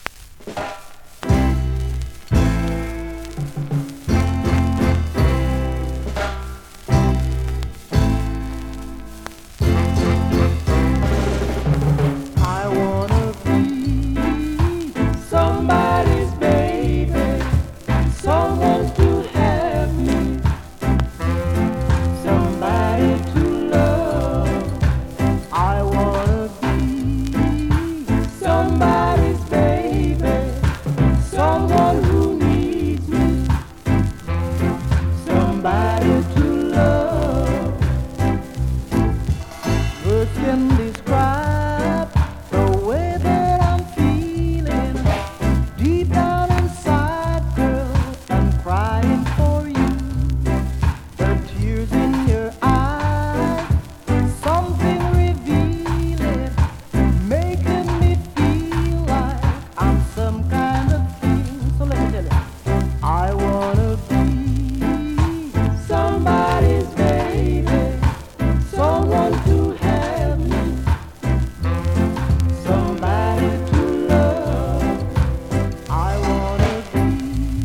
(両面ともクモリ有、うすくジリノイズ有)
スリキズ、ノイズ比較的少なめで